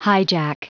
Prononciation du mot highjack en anglais (fichier audio)
Prononciation du mot : highjack